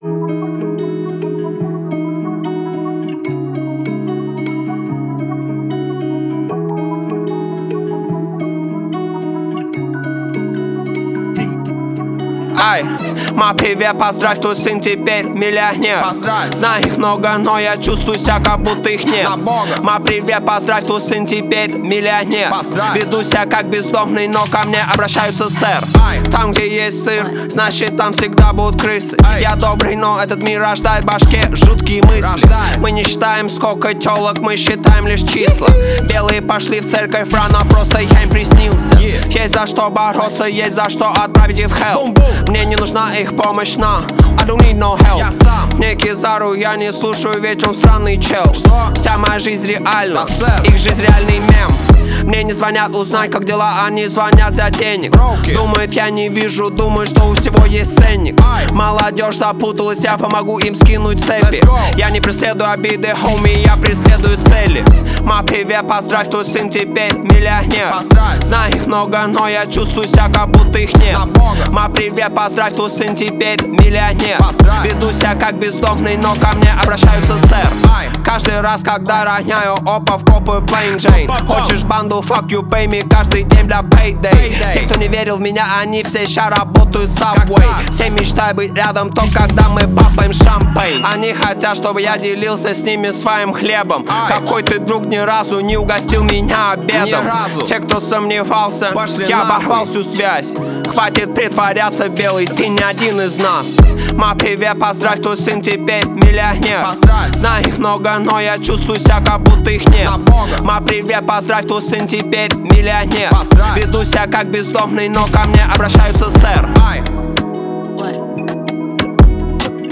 Качество: 320 kbps, stereo
Рэп